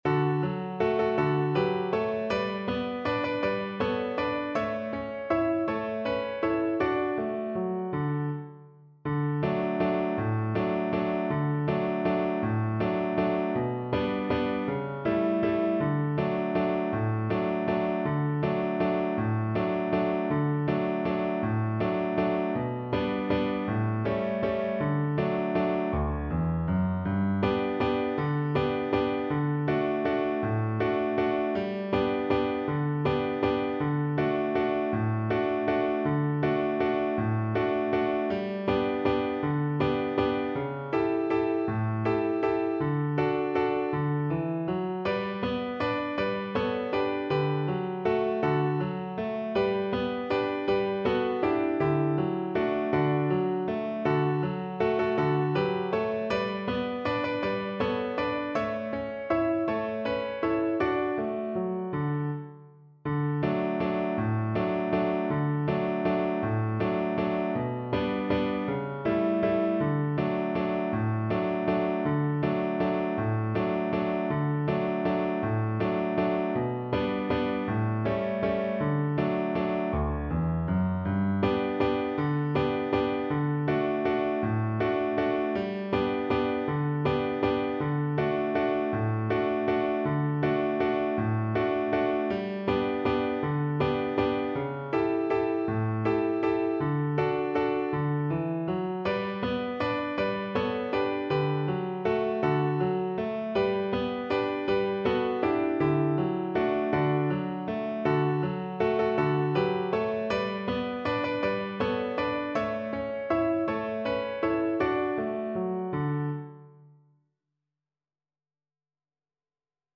3/4 (View more 3/4 Music)
One in a bar =c.160